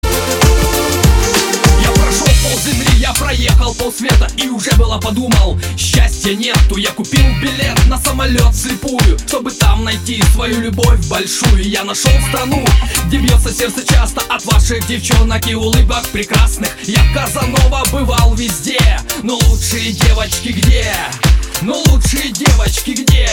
• Качество: 192, Stereo
поп
позитивные
мужской вокал
веселые